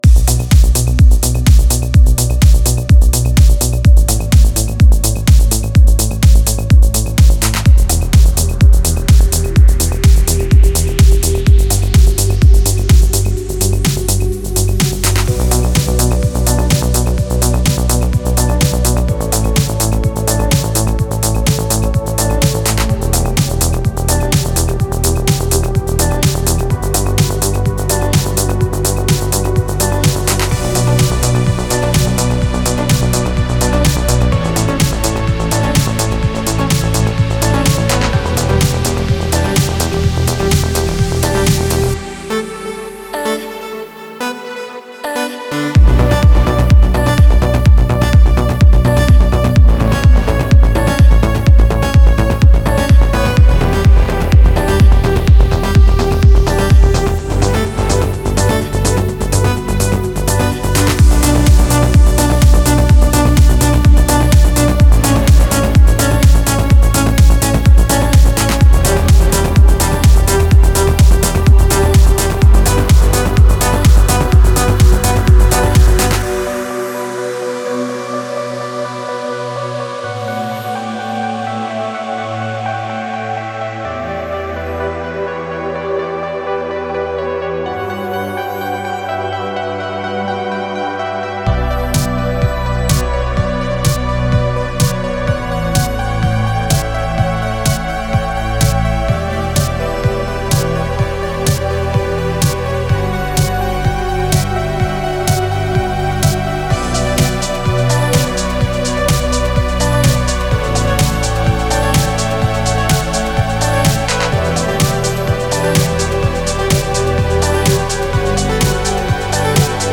захватывающая электронная композиция